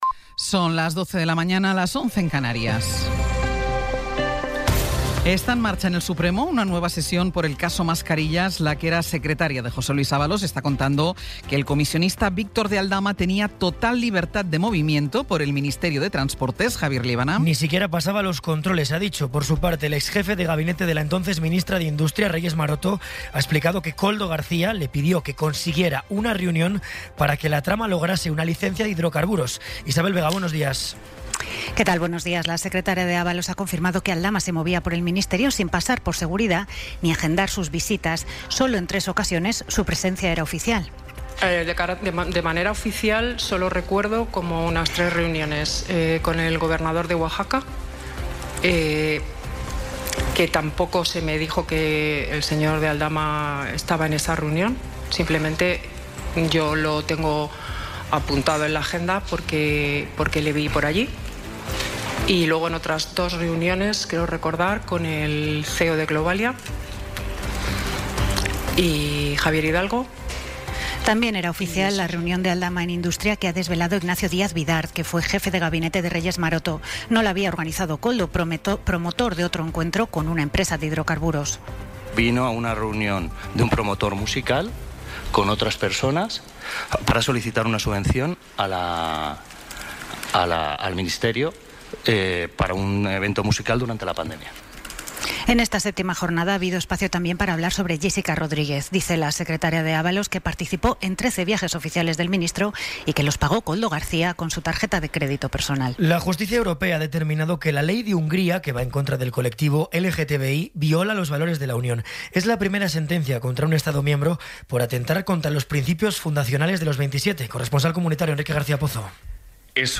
Resumen informativo con las noticias más destacadas del 21 de abril de 2026 a las doce.